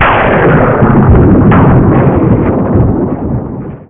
Thunderstorm to replace electro sound *46kb*
zap.wav